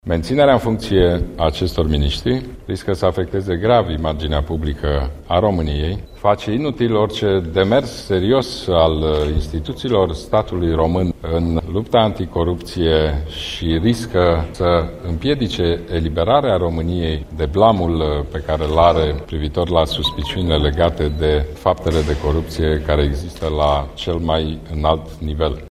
Preşedintele PNL, Ludovic Orban, a declarat că, în cazul în care aceştia nu vor demisiona, liberalii vor cere premierului demiterea lor: